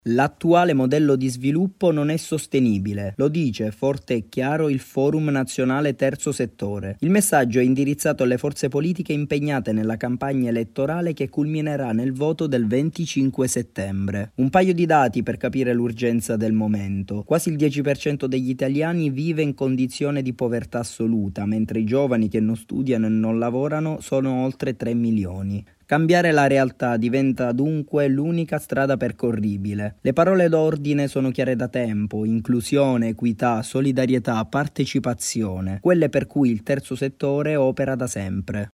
A un mese dalle elezioni il Forum Terzo settore chiede alle forze politiche di contrastare povertà e disuguaglianze. Il servizio